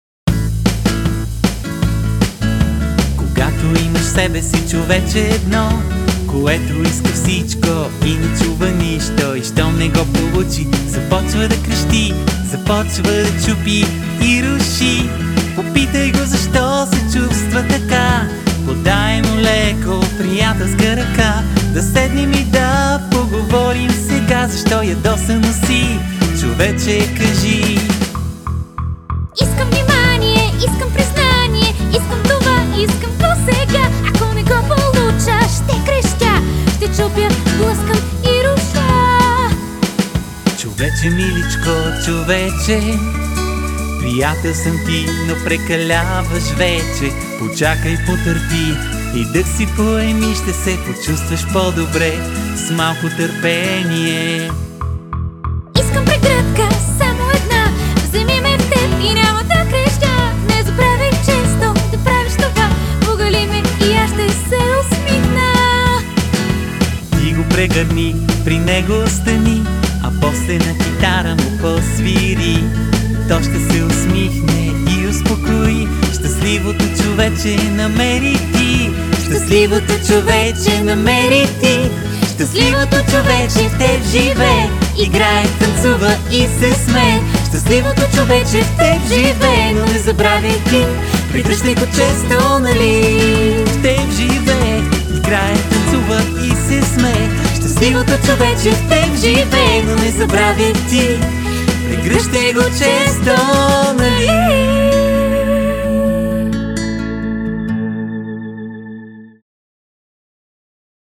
20 авторски детски песнички